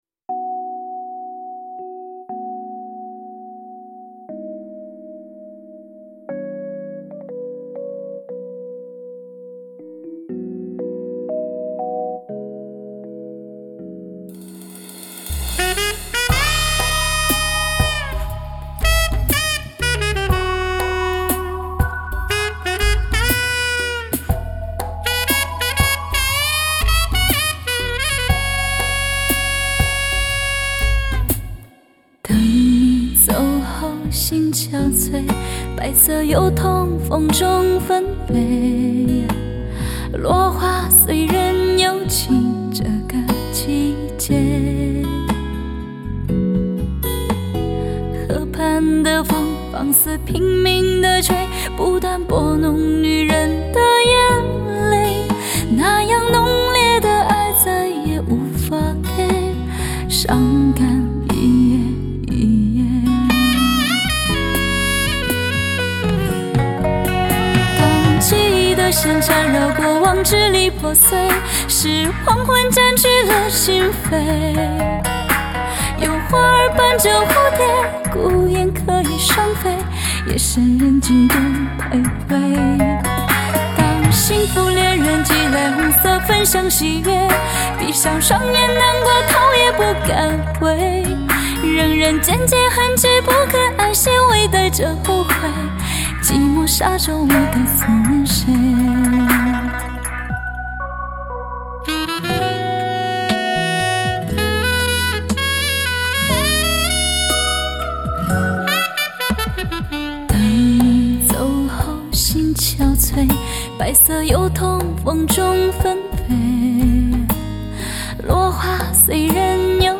她的歌甜美中带着丝丝幽怨和清冷
顶级的编配吸引你的耳朵
绝佳的录混让你一听难忘
音色淳厚优美，典雅华丽而委婉！